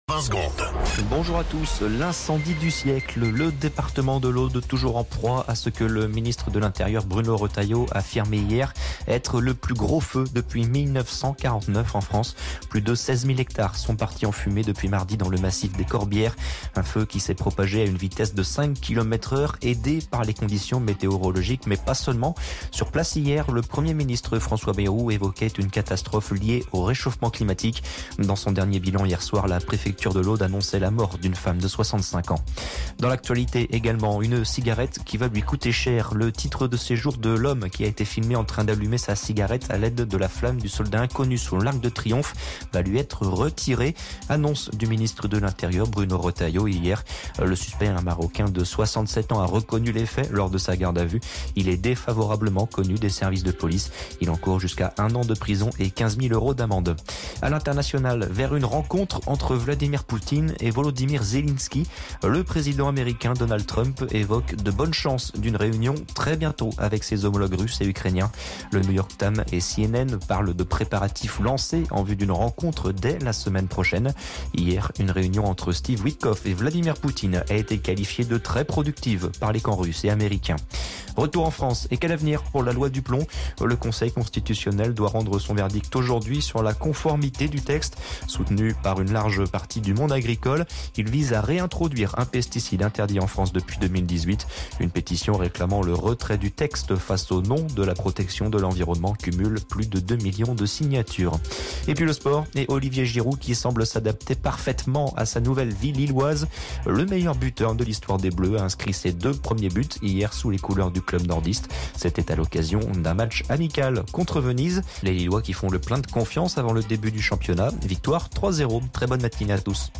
Flash Info National 07 Août 2025 Du 07/08/2025 à 07h10 .